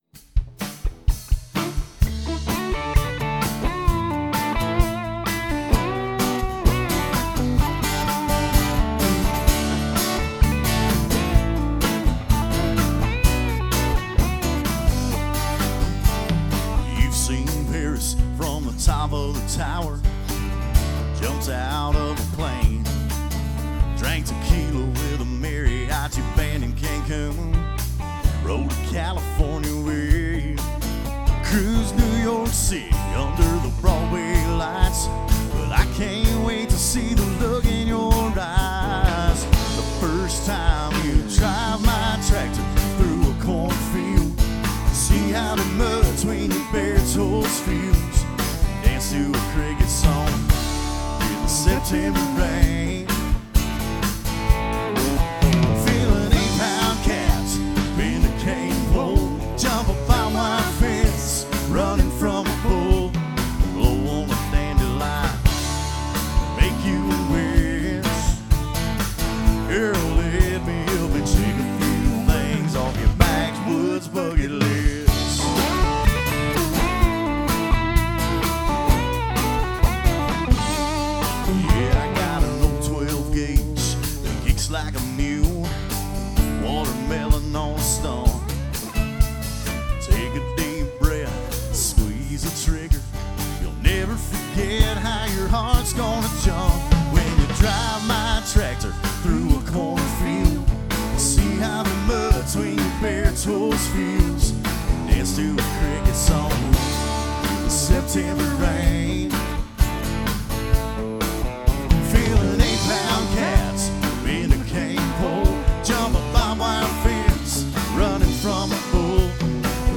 live at Wild Wing Cafe in Cool Springs, TN April 15, 2016.